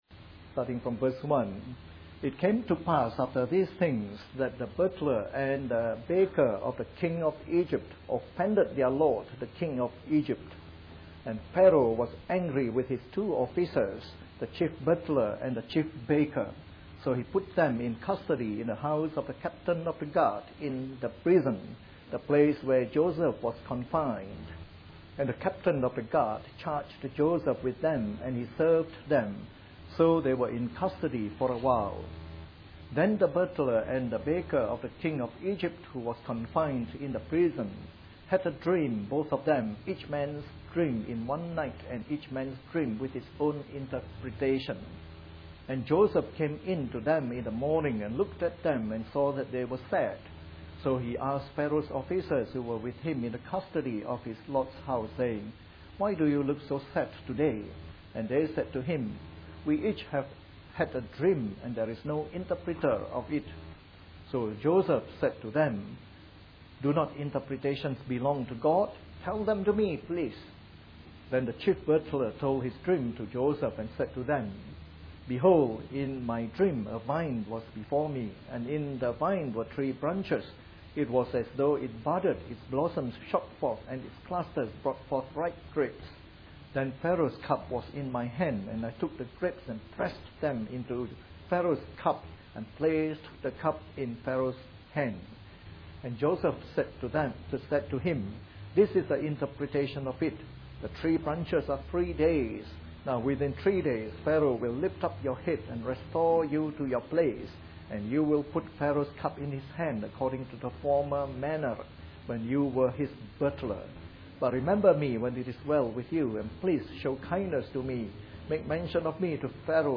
Part of our series on the Book of Genesis delivered in the Morning Service.